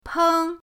peng1.mp3